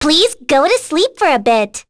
Rehartna-Vox_Skill3_b.wav